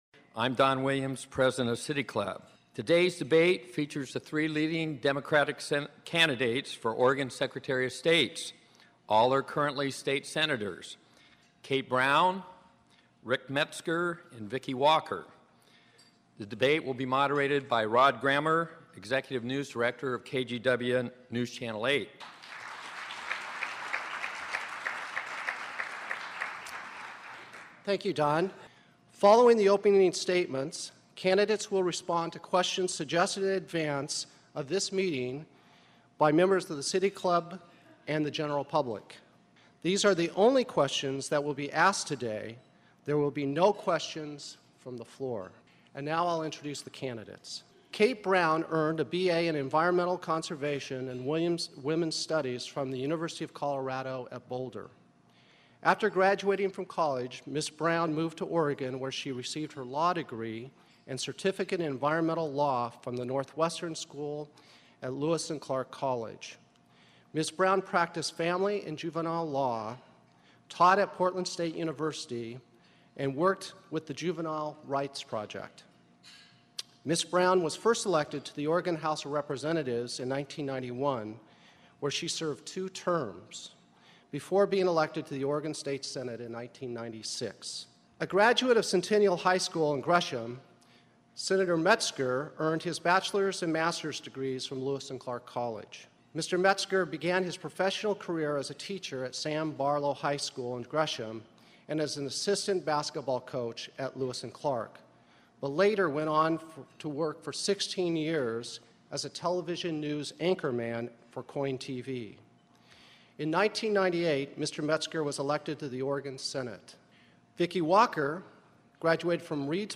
Secretary of State Debate